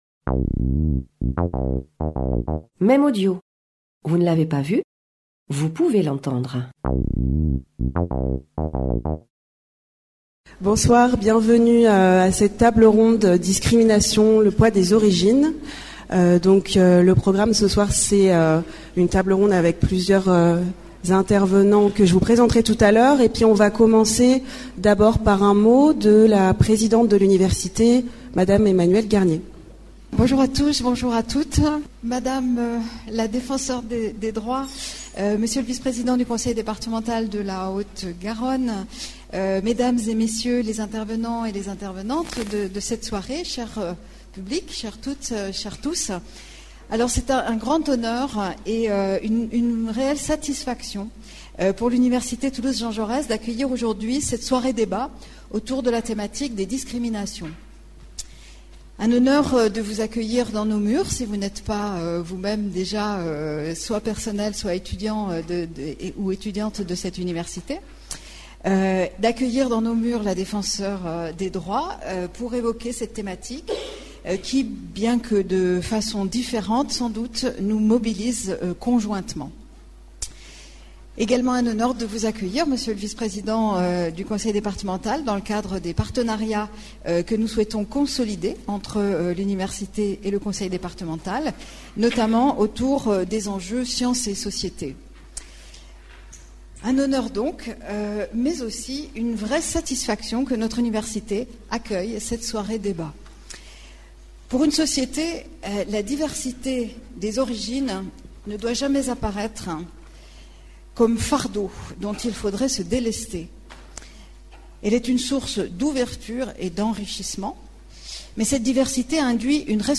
Conférence et table ronde
Conférence introductive de Claire Hédon (Défenseure des droits)